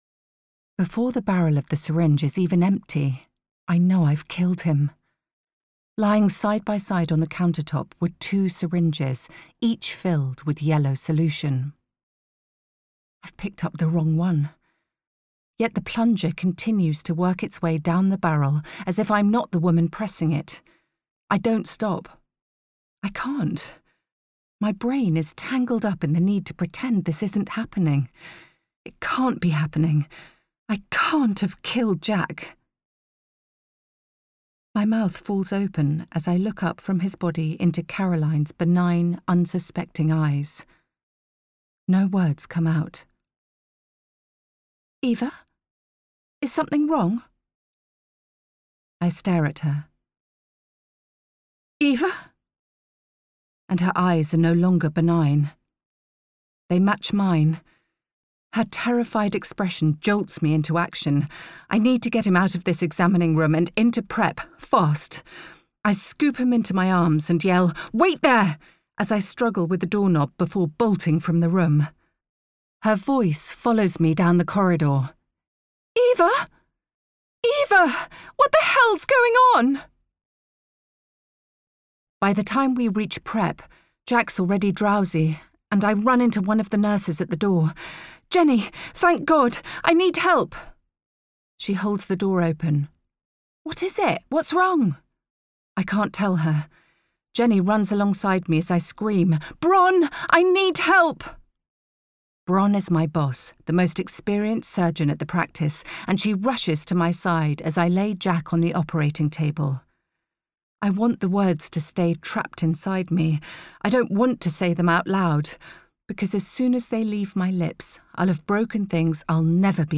When HE’S Not HERE (Audiobook)
Performed by Emilia Fox (The Pianist, Silent Witness).